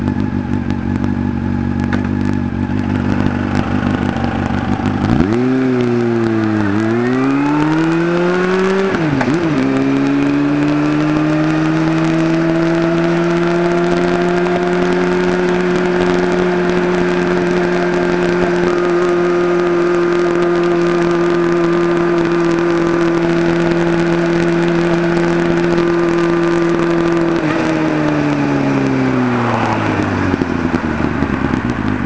※マフラーがモリワキ フルチタン ANOになっていますので、ノーマルではもう少し違った乗り味、印象、音になると思います。
A　ノーマルVTECでのゼロ発進加速。加速していく途中でギアチェンジとは違うエキゾーストノートの変化が聞き取れます。
A　ノーマルVTEC